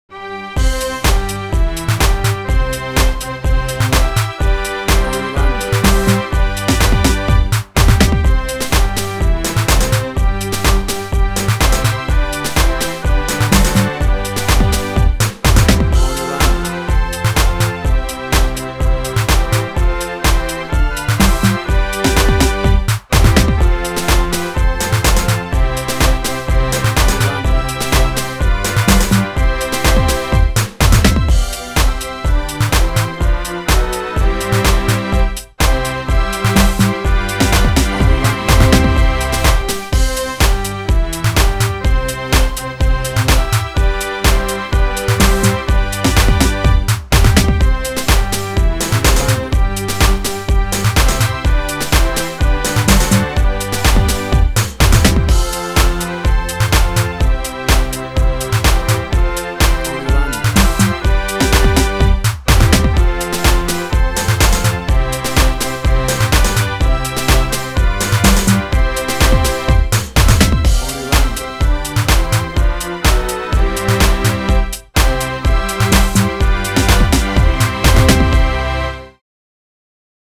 A pumping energetic dance remix
WAV Sample Rate: 32-Bit stereo, 44.1 kHz